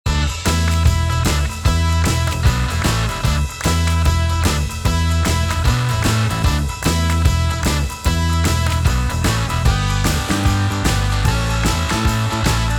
サウンドデモ
ミックス（SA-3適用後）
SA-3_MixBus_Engaged.wav